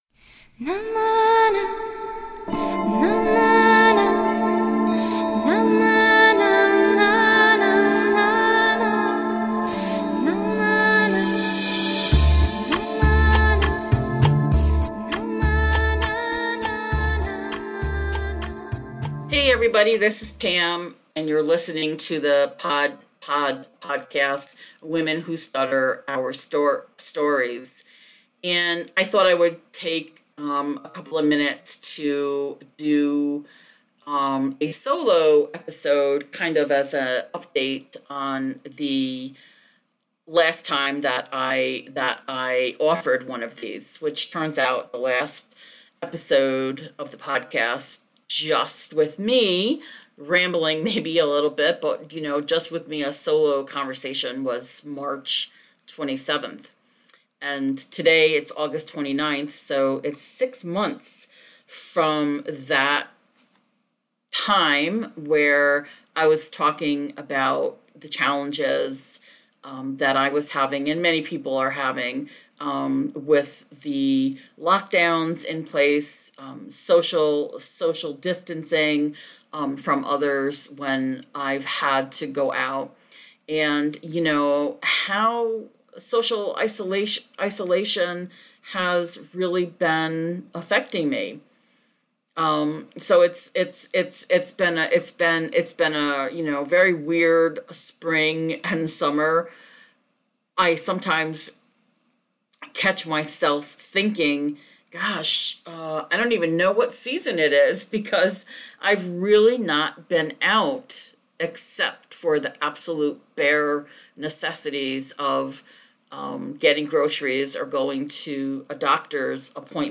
Today I bring you a short episode with no guest, just me, talking about what is has been like during the pandemic lock down.
I figured it was about time that I bring you another rambling monologue about stuttering. In this episode, I talk about giving our stuttering way more “head space” than we should. And I also talk about how we are affected by constantly seeing ourselves on screen in a little box when we are doing so many video chats.